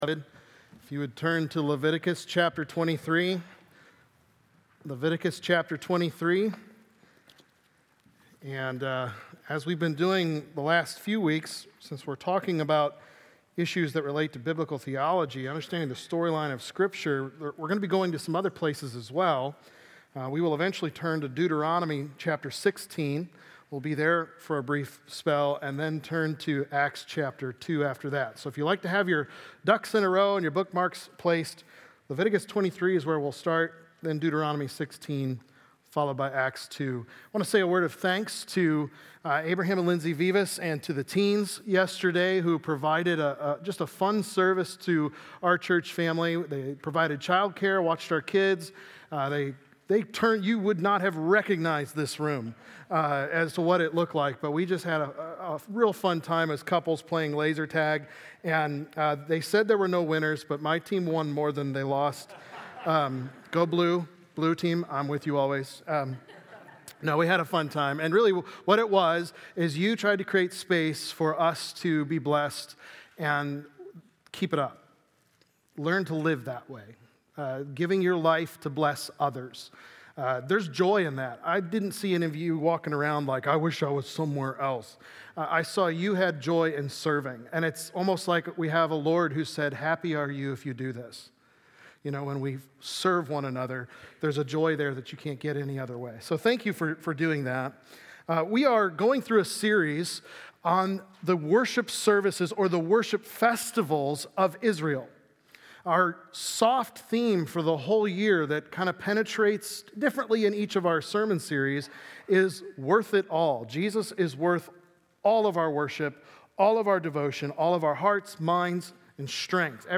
Worship Rhythms: Pentecost | Baptist Church in Jamestown, Ohio, dedicated to a spirit of unity, prayer, and spiritual growth